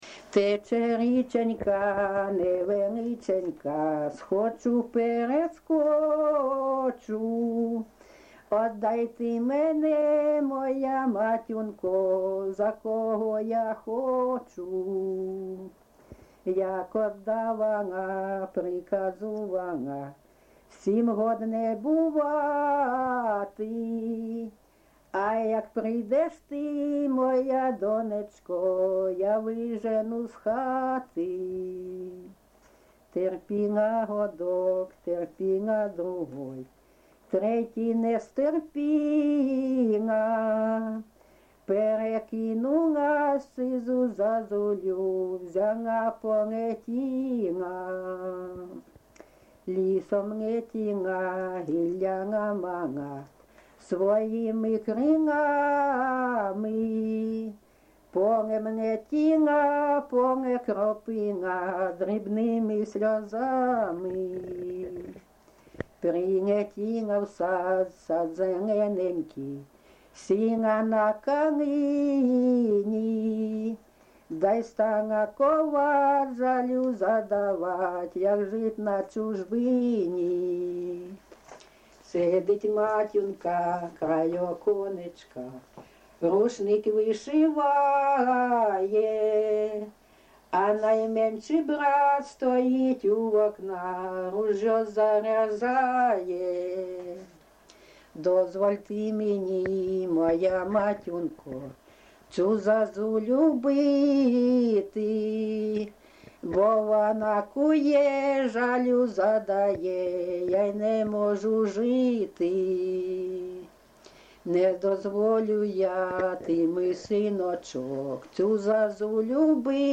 ЖанрПісні з особистого та родинного життя, Балади
Місце записус. Курахівка, Покровський район, Донецька обл., Україна, Слобожанщина